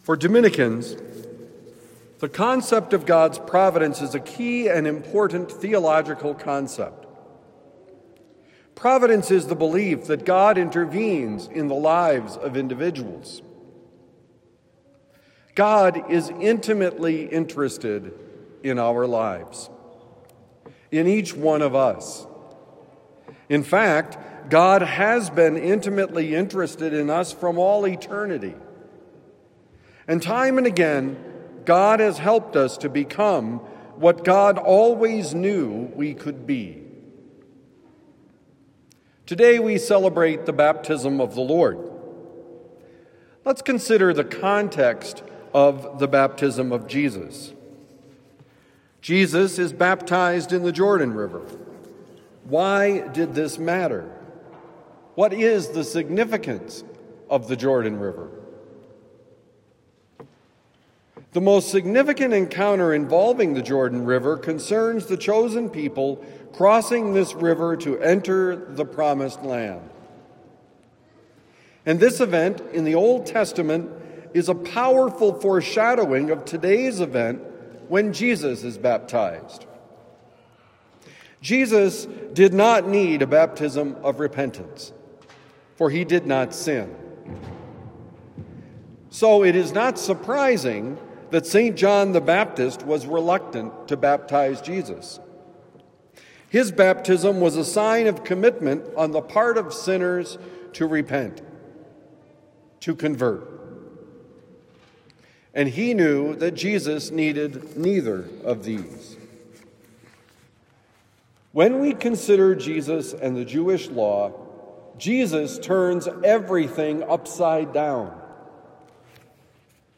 Celebrate Your Baptism: Homily for Sunday, January 11, 2026